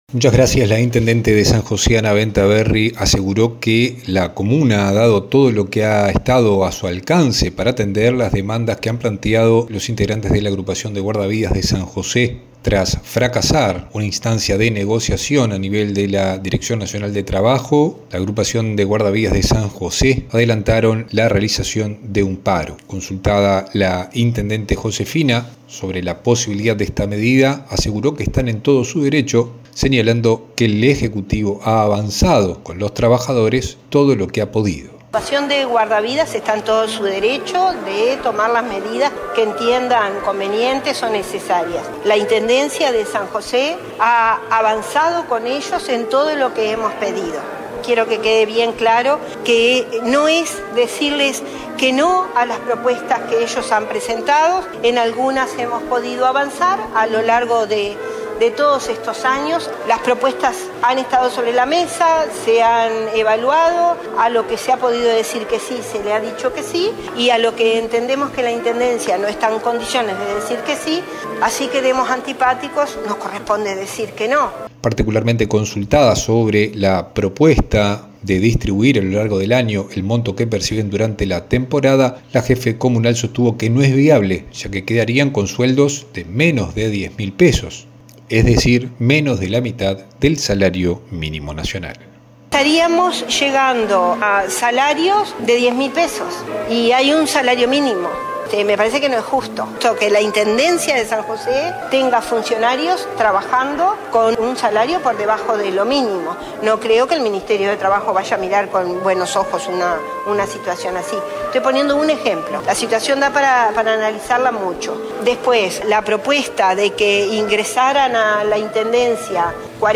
La intendenta de San José, Ana Bentaberri, se refirió a la situación del conflicto con la Agrupación de Guardavidas del departamento, tras no alcanzarse un acuerdo en la última instancia de negociación celebrada en la DINATRA.